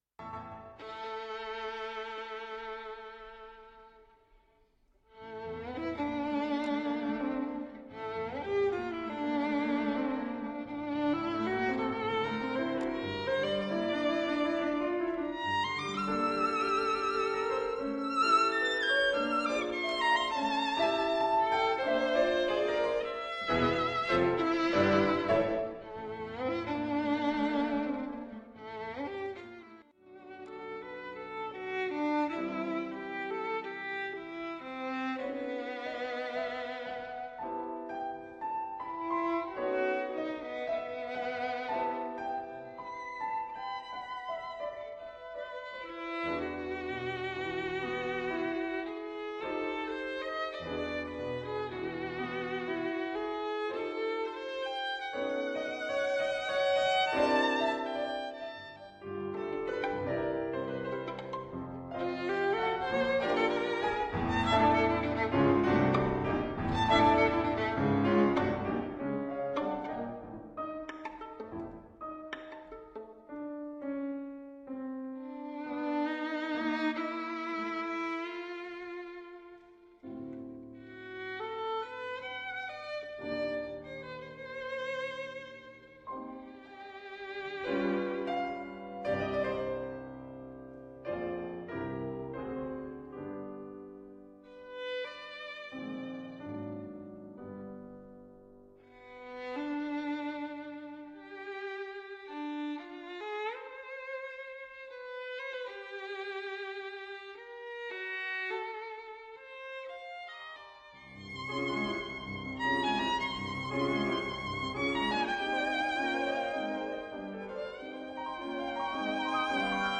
>>> Posłuchaj fragmentu MIX mp3<<< Joaquin Turina (1882-1949) The Three Sonatas for Violin and Piano - Sonata No. 1 Op. 51 (dedicated to Jeanne Gautier) - Sonata No. 2 Op. 82 (dedicated to Pedro Sunjan) - Sonata Espanola (190-8)